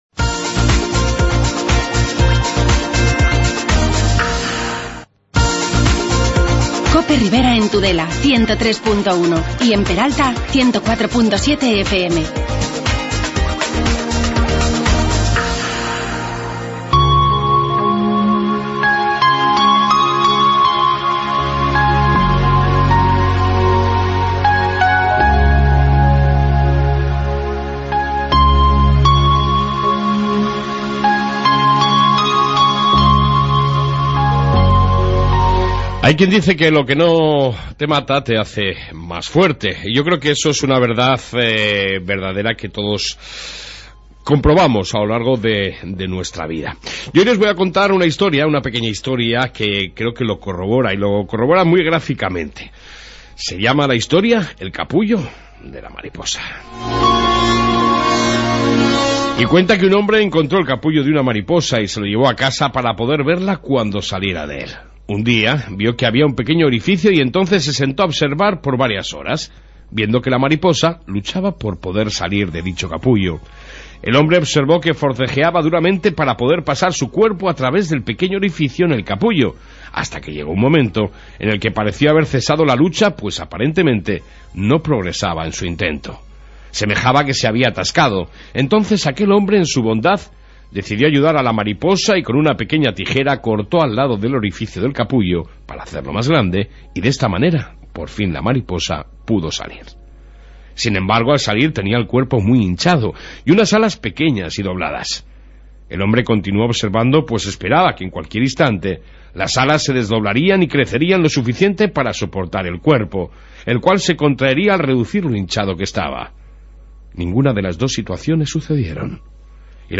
AUDIO: Amplia Entrevista